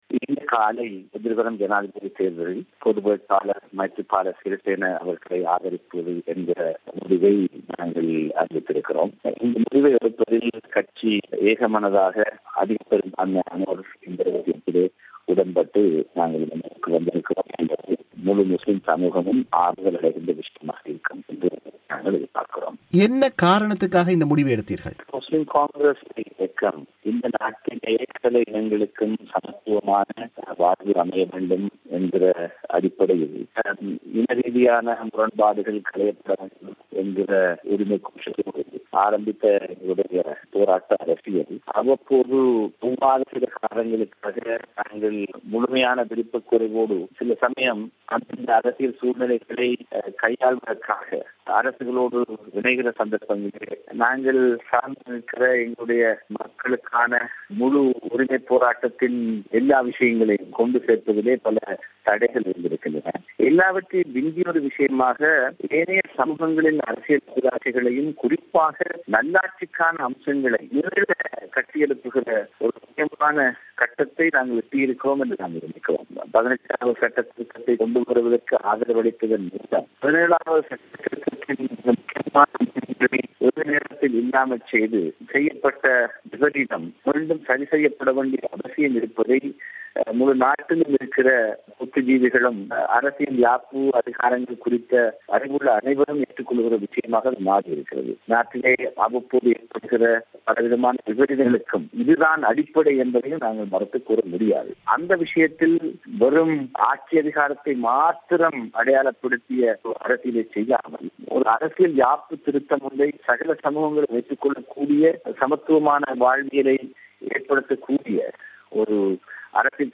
அவர் பிபிசிக்கு வழங்கி செவ்வியை நேயர்கள் இங்கு கேட்கலாம். இந்தச் செவ்வியின் ஒலிவடிவத்தின் தரம் சற்று குறைவாக இருப்பதற்காக வருந்துகிறோம்.